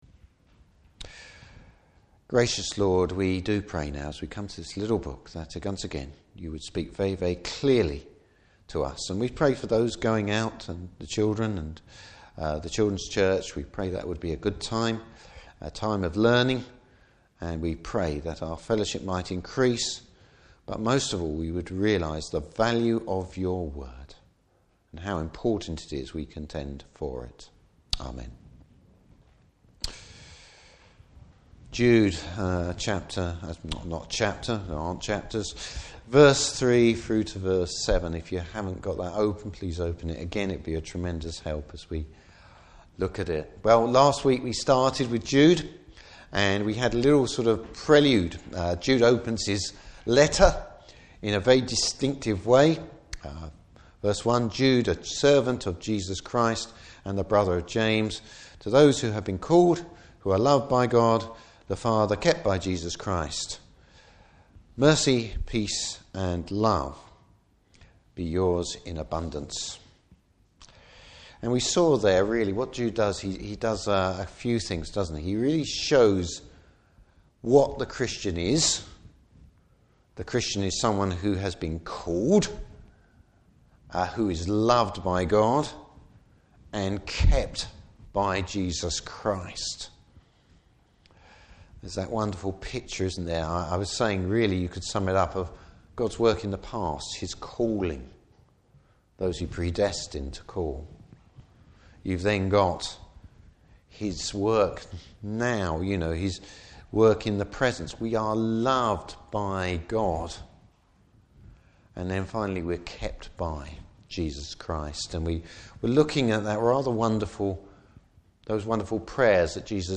Service Type: Morning Service Danger from within the Church!